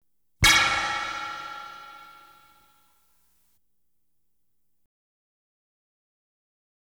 Light Beam Hit Sound Effect
Download a high-quality light beam hit sound effect.
light-beam-hit-3.wav